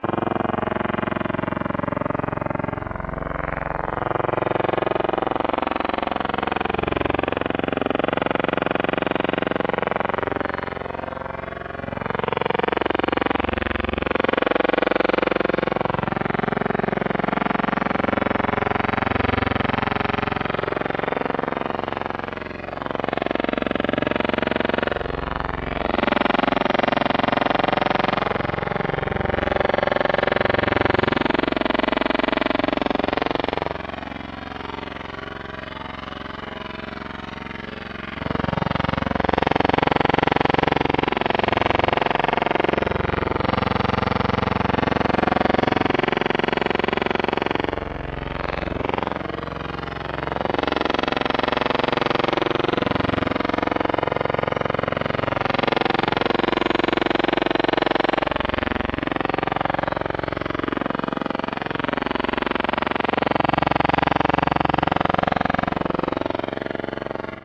某种引擎1
描述：某种机械噪音......可能来自气动钻机。通过计算机合成生成样本。
Tag: 钻孔 建筑的 机械的钻头 机械 噪声 机器 工厂 工作 气动 工业